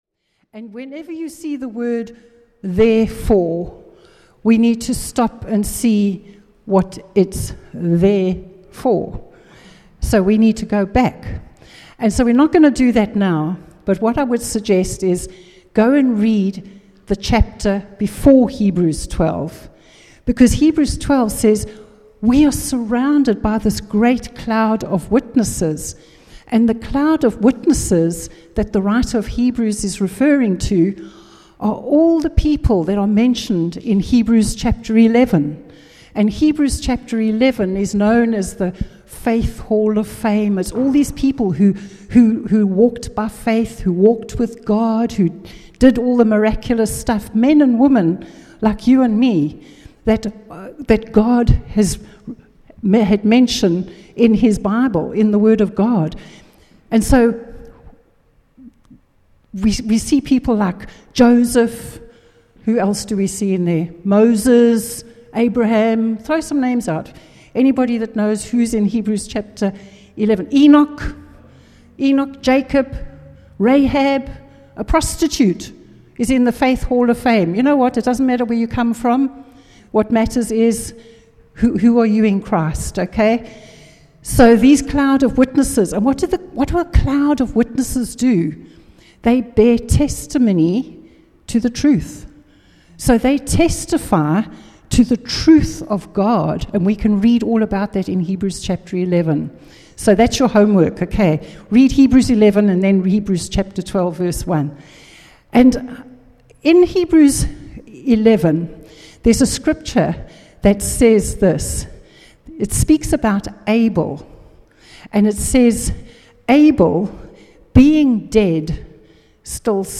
Our personal testimonies are eternal, powerful, and bring glory to Jesus, and during Celebration Sunday we heard wonderful testimonies of what God has been doing in 3 people's lives over the last year.